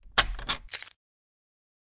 描述：枪的声音重新加载。
标签： 弹药 重新加载
声道立体声